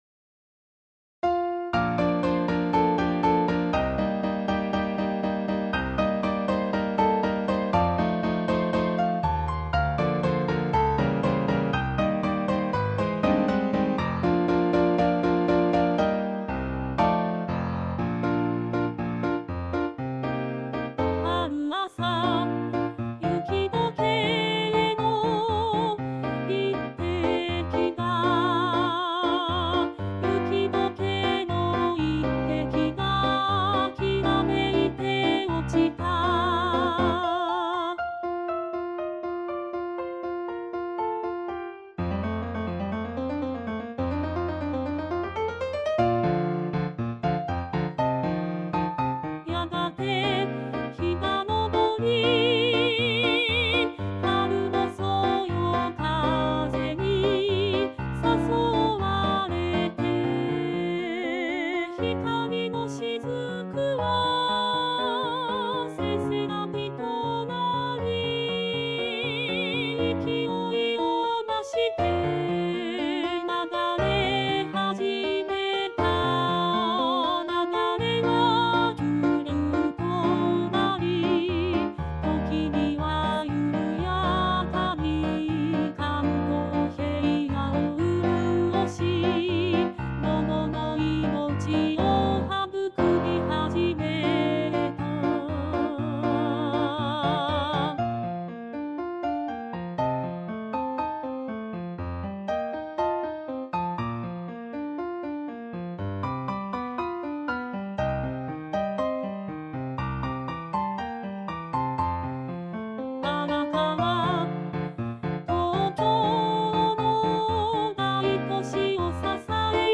ジョイントコンサート練習曲
●パート単独音源　　　■Rchソプラノ、Lch、アルト、テノール、バス
jokyoku_arakawa_alto_sita_only.mp3